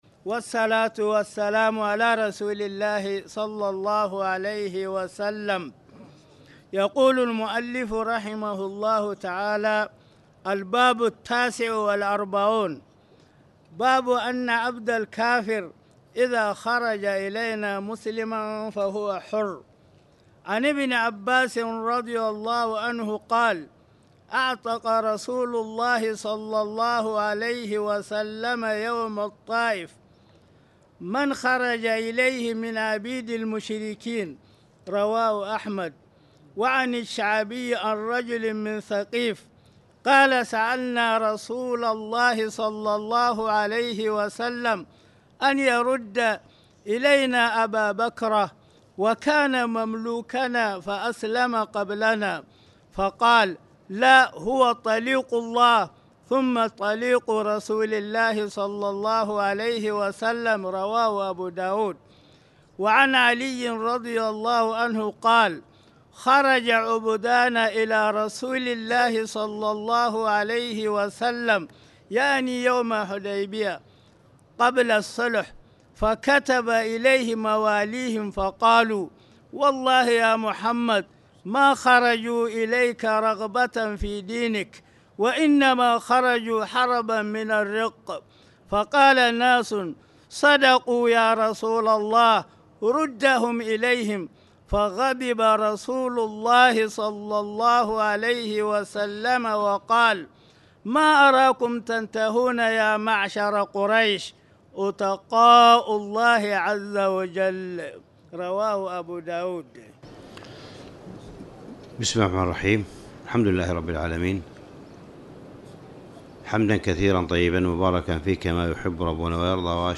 تاريخ النشر ٤ جمادى الأولى ١٤٣٨ هـ المكان: المسجد الحرام الشيخ: معالي الشيخ أ.د. صالح بن عبدالله بن حميد معالي الشيخ أ.د. صالح بن عبدالله بن حميد باب أن الحربي إذا أسلم قبل القدرة عليه